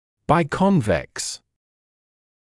[baɪ’kɔnveks][бай’конвэкс]двояковыпуклый